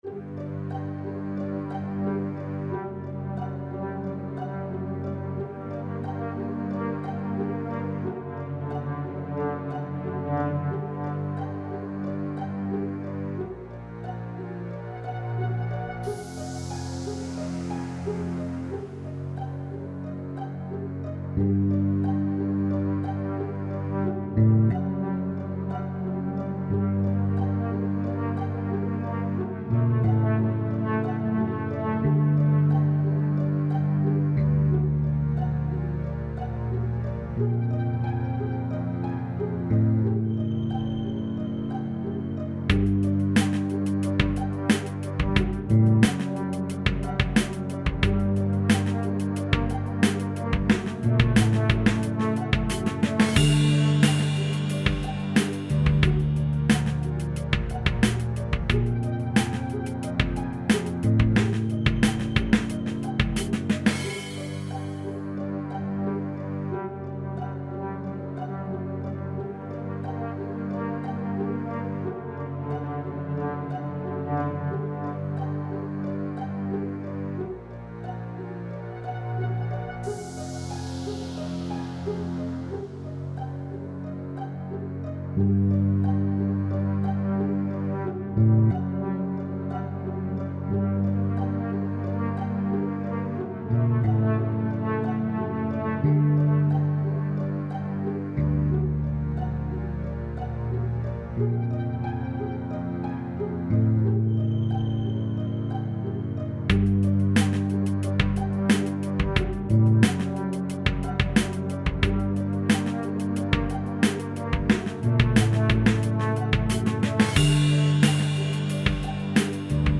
Some suspenseful background music